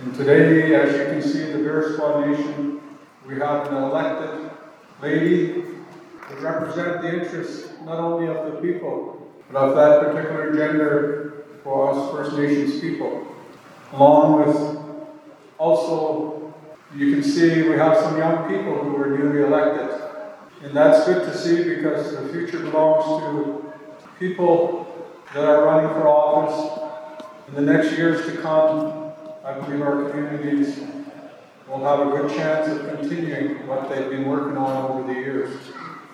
Bearspaw First Nation held an Inauguration Ceremony for their elected Chief and Council
During a speech at the Inauguration, Chief Dixon expressed his thanks.